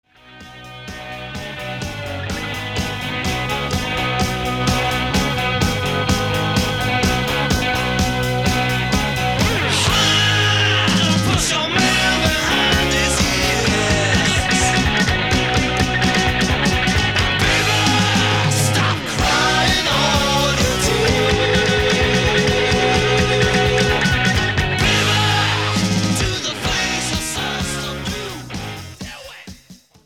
Recorded Location:Bell Sound Studios, New York City
Genre:Hard Rock, Heavy Metal